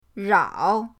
rao3.mp3